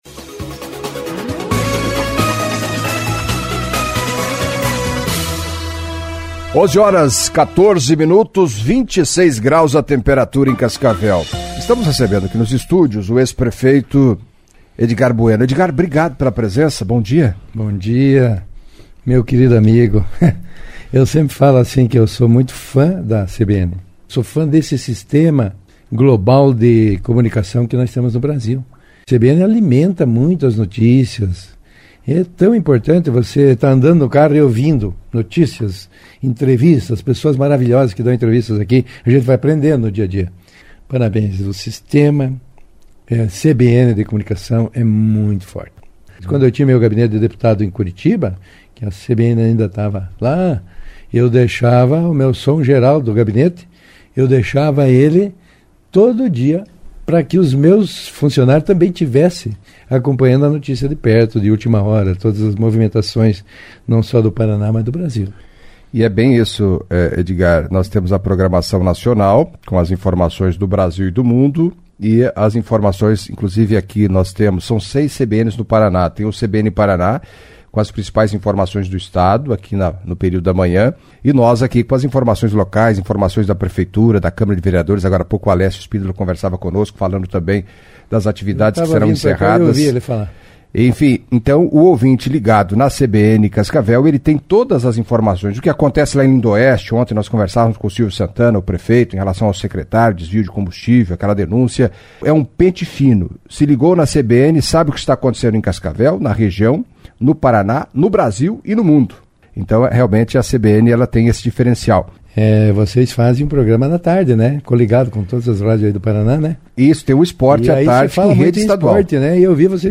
Em entrevista à CBN Cascavel nesta terça-feira (20), o empresário e ex-prefeito Edgar Bueno deixou uma mensagem de Natal e de Ano Novo aos ouvintes e comentou sobre seu, possível, futuro político.